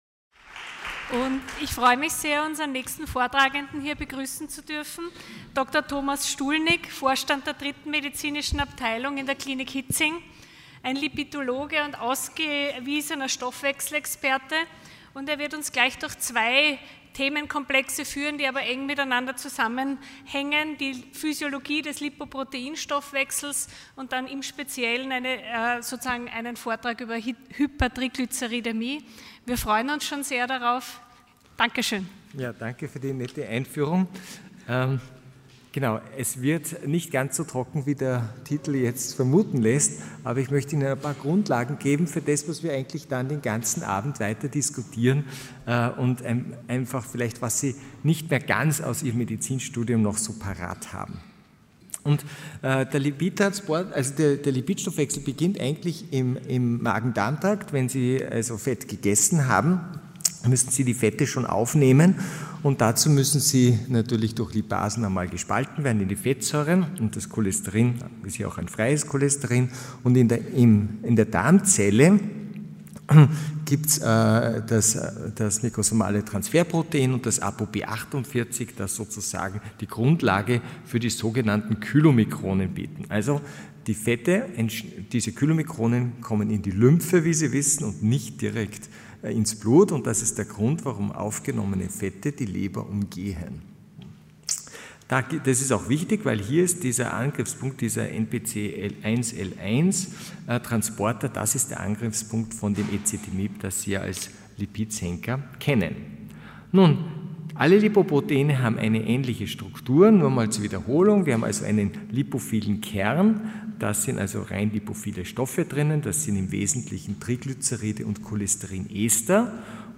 Sie haben den Vortrag noch nicht angesehen oder den Test negativ beendet.